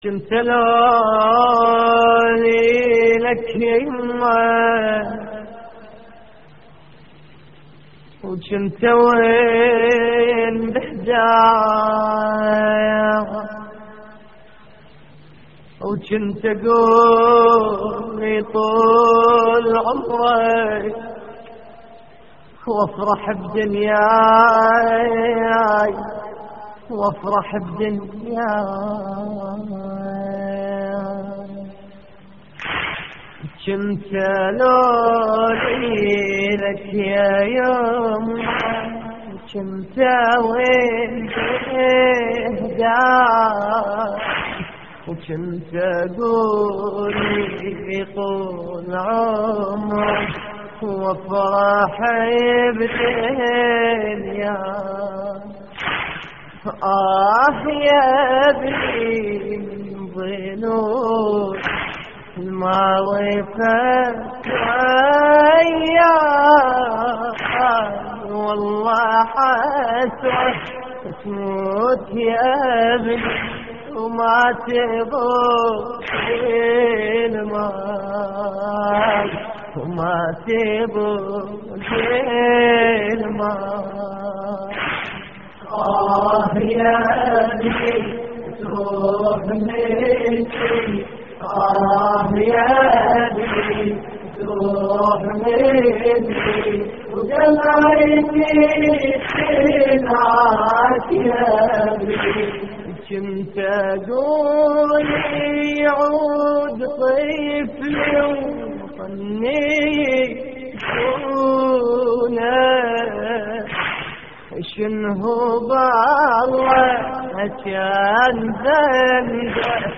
الرادود
استديو